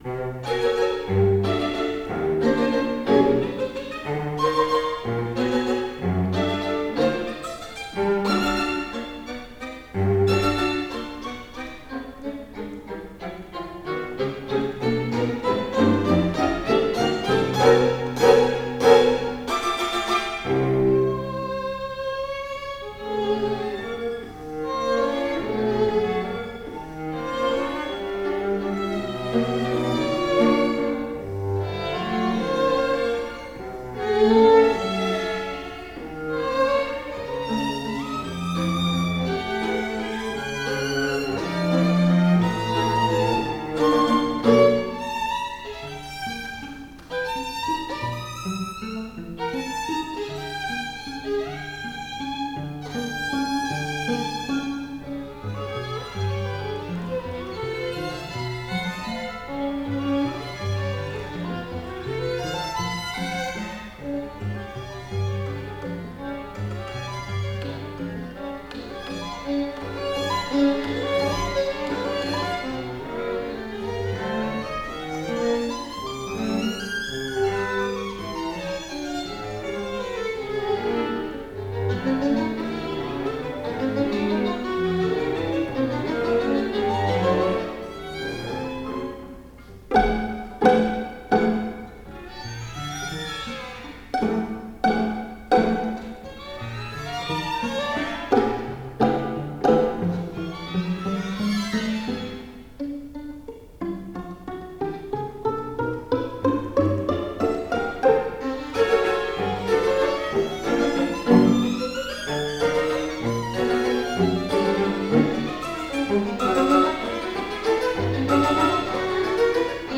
ORCHESTRA (Non-Vocal)
string orchestra version